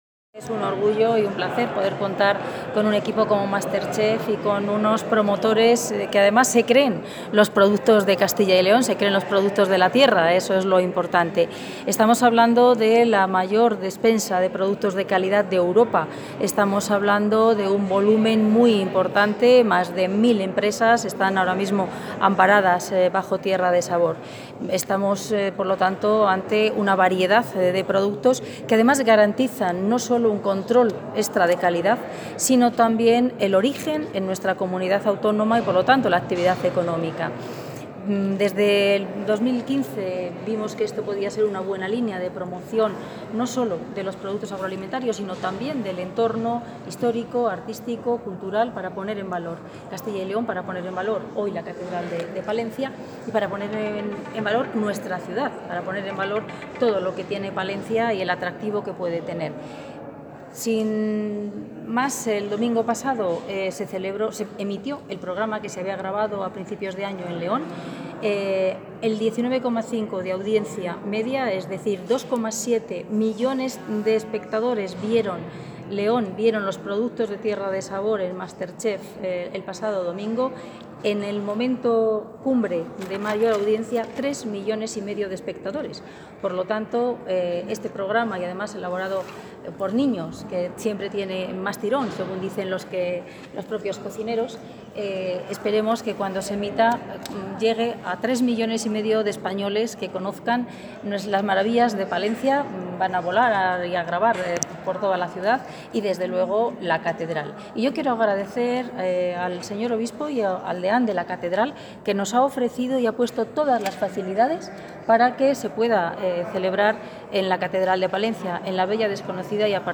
Consejera de Agricultura y Ganadería.
Consejera de Agricultura y Ganadería Grabación de MasterChef Junior, con Tierra de Sabor, en Palencia Grabación de MasterChef Junior, con Tierra de Sabor, en Palencia Grabación de MasterChef Junior, con Tierra de Sabor, en Palencia